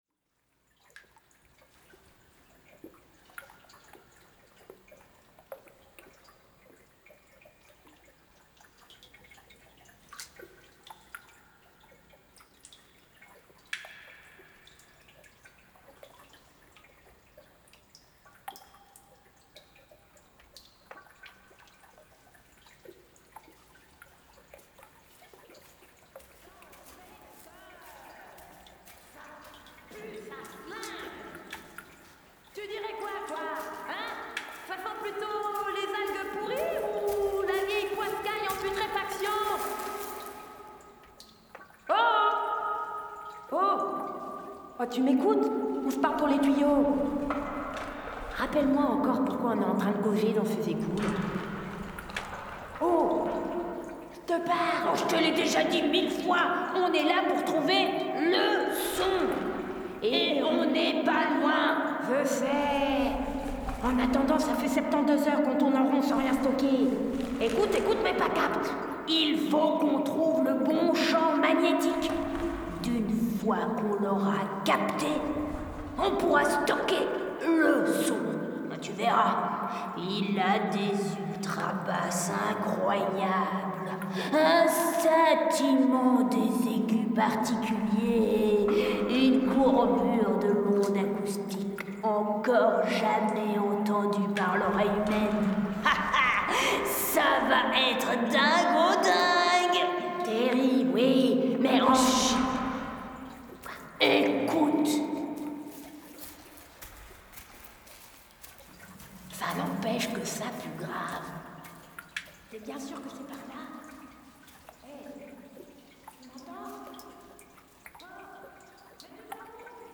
Audiowalk
Mêlant texte et univers sonore, cette performance est une immersion dans la richesse stupéfiante de la biodiversité́ acoustique qui nous entoure.